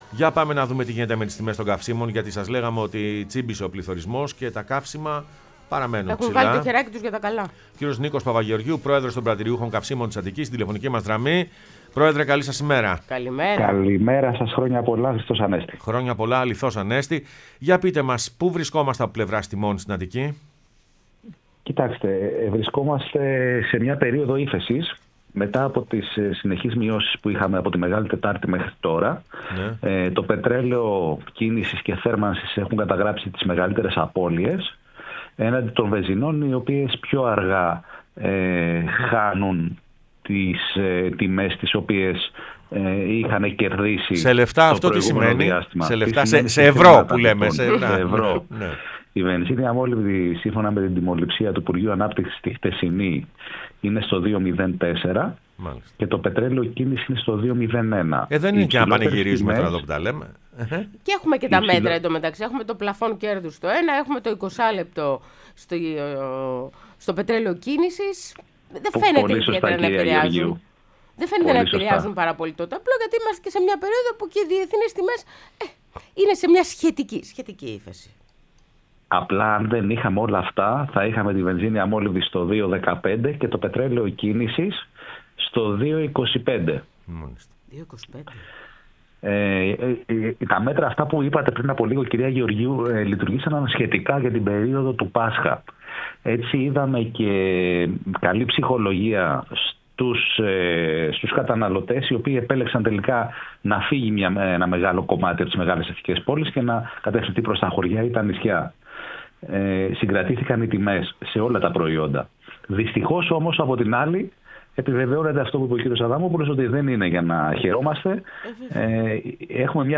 μίλησε στην εκπομπή «Πρωινές Διαδρομές»